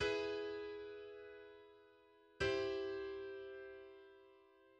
For example, in B major, the IV (subdominant) triad in root position contains the notes E, G, and B. By lowering the G by a semitone to G and raising the B to C, the Neapolitan sixth chord E–G–C is formed.